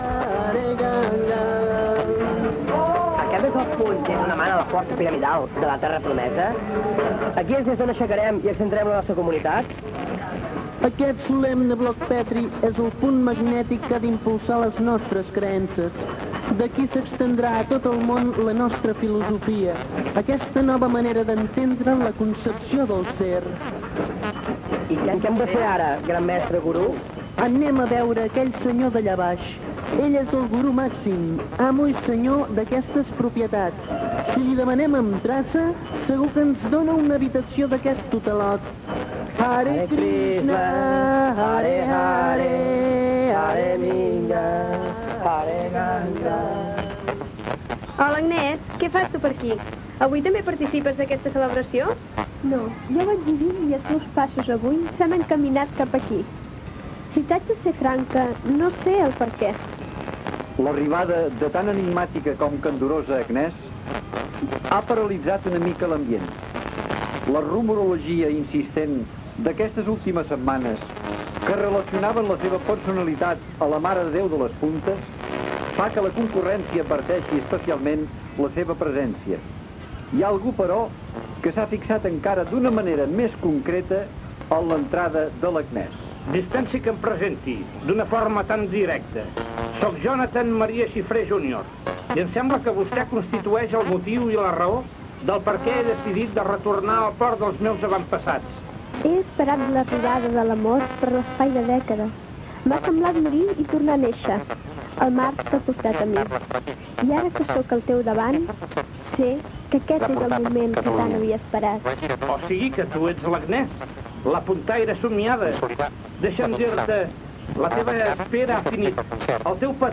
82975b5d2e0f8a36f0a1dd20f79abdd88a7ca93a.mp3 Títol Ràdio Arenys Emissora Ràdio Arenys Titularitat Pública municipal Nom programa Qui és l'última? Descripció Final del l'últim capítol (62) de la radionovel·la "Qui és l'última" (1990). Gènere radiofònic Ficció